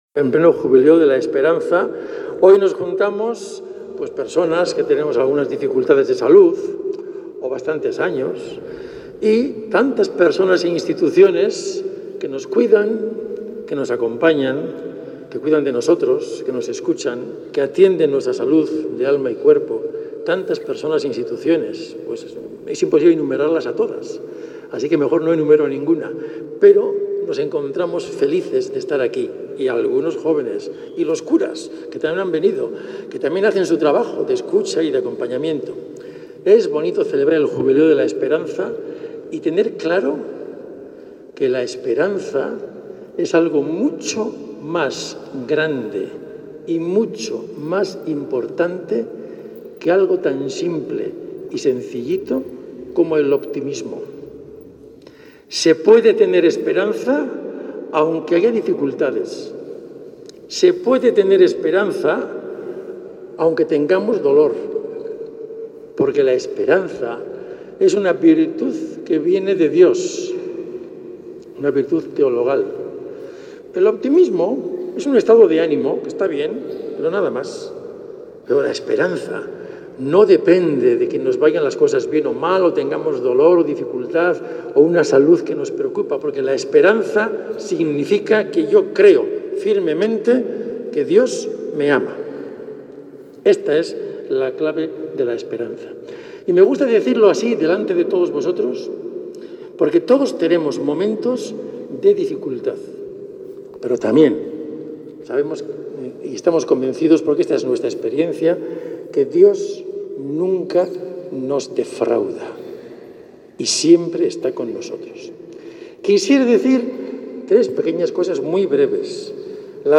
En el marco del Año Jubilar, la celebración tuvo lugar en la basílica de san Lorenzo y se convirtió en una acción de gracias por la labor de aquellos que curan, acompañan y consuelan a personas necesitadas.